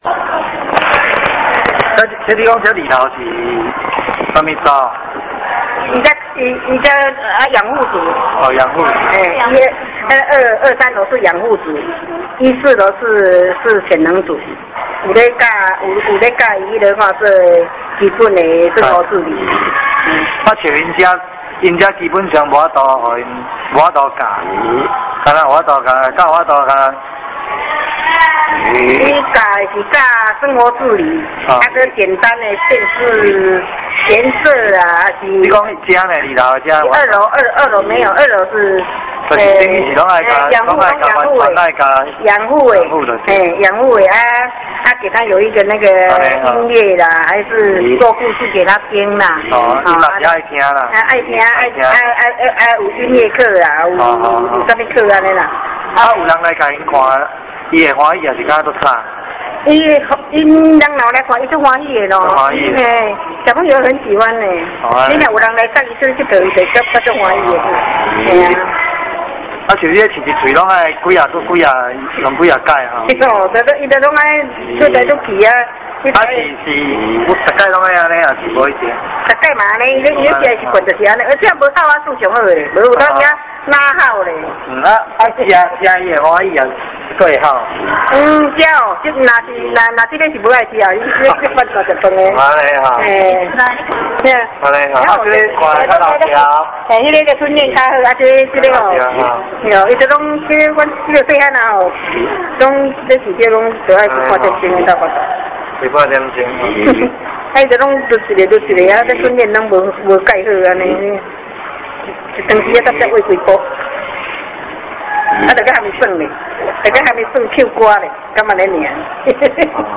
訪談地點：嘉義縣東石鄉聖心教養院二樓
【訪談錄音播放】